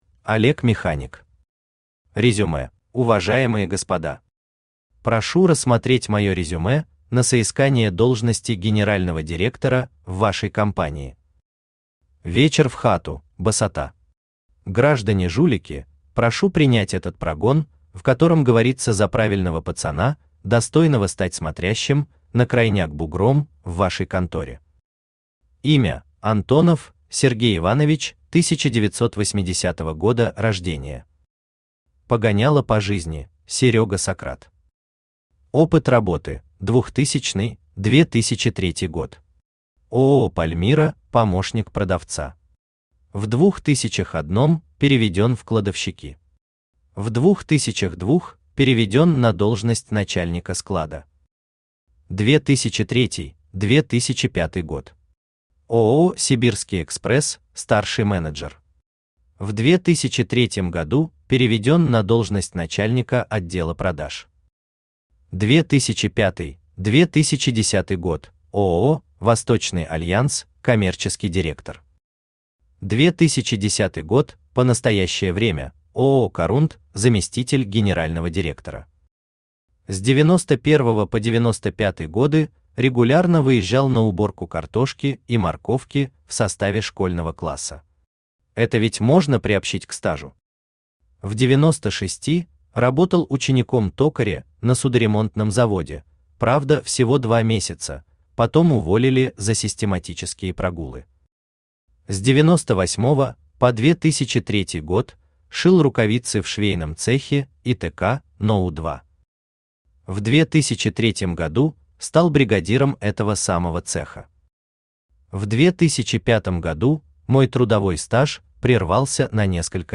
Aудиокнига Резюме Автор Олег Механик Читает аудиокнигу Авточтец ЛитРес.